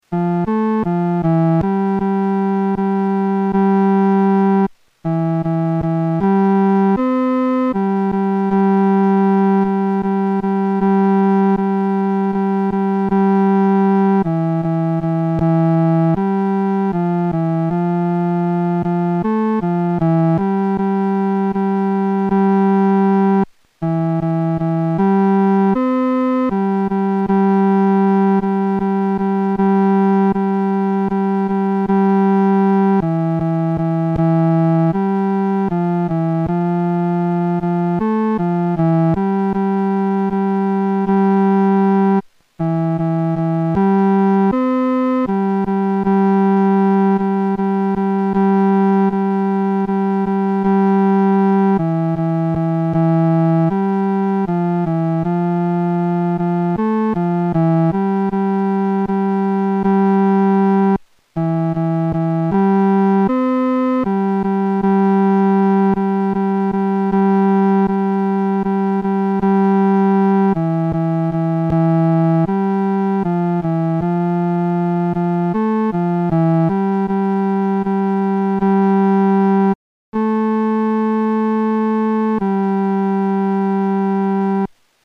女低
其旋律、和声构成无比宁静的气氛，在丧礼中给人莫大的安慰。